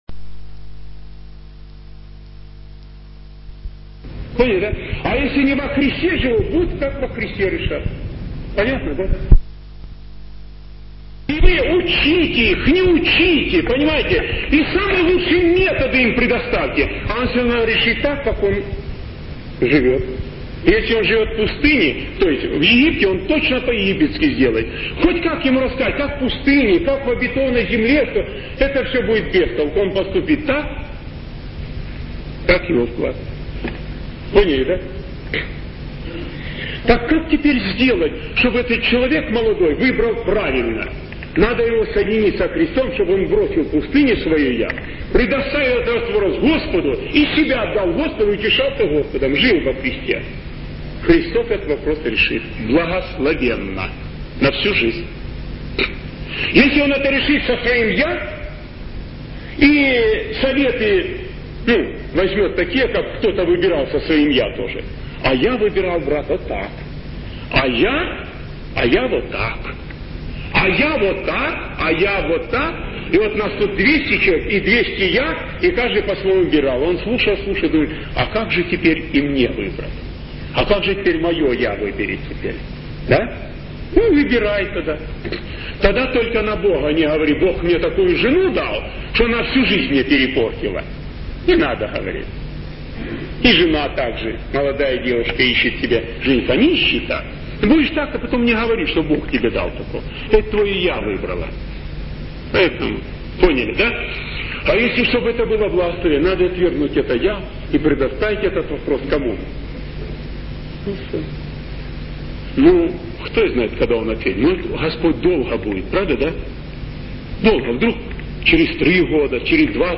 Аудио проповеди